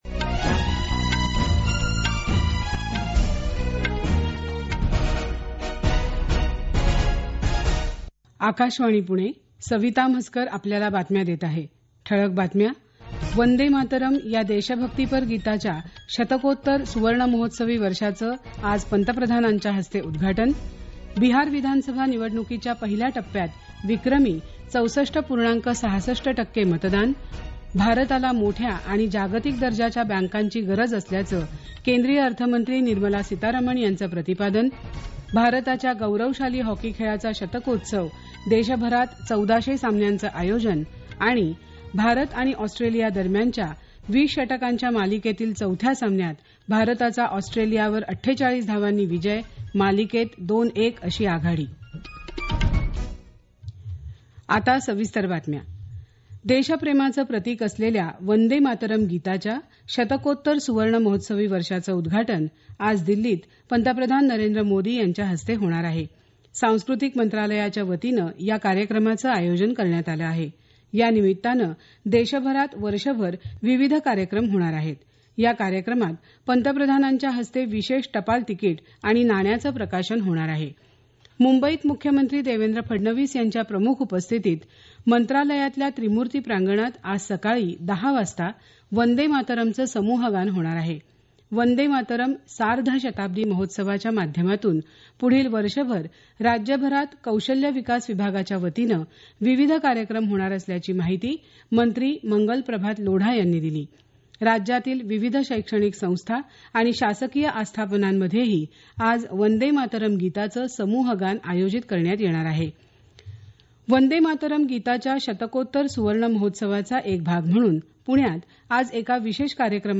NATIONAL-NEWS-MARATHI-2.mp3